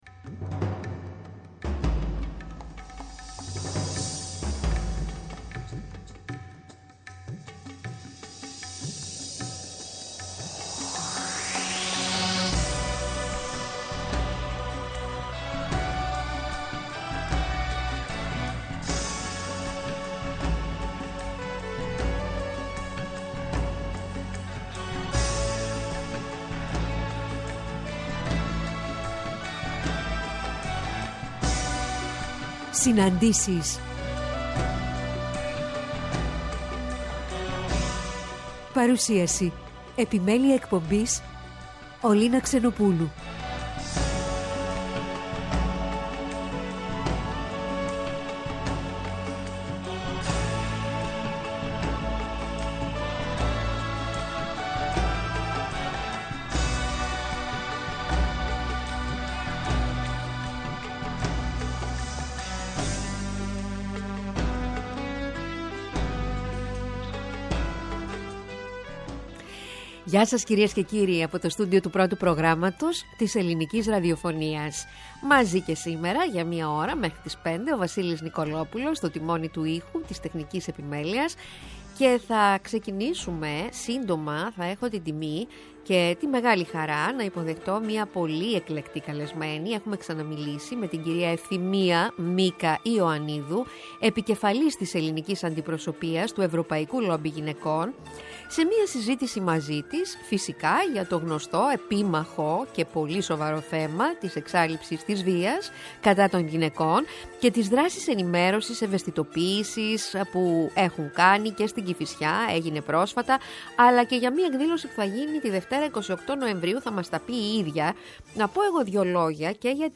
Σάββατο 26-11-22 ώρα 16:00-17:00 στις Συναντήσεις στο Πρώτο Πρόγραμμα: